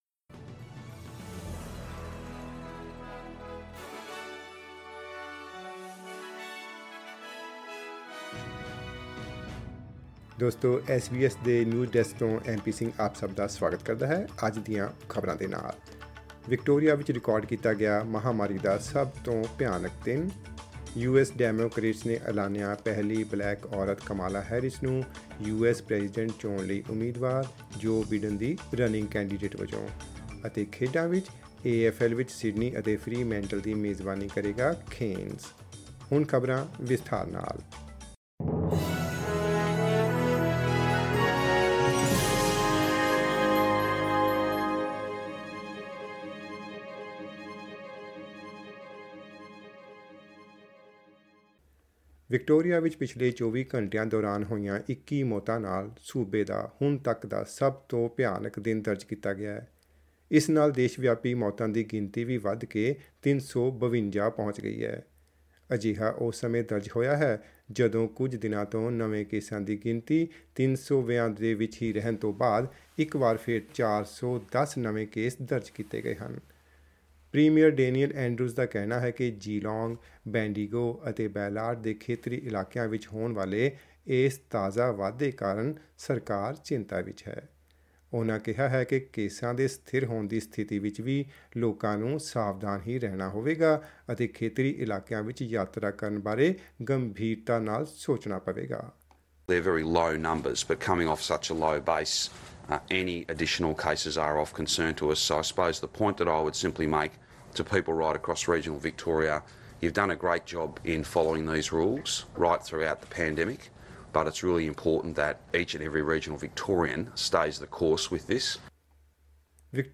news_podcast_with_jingles-mp3.mp3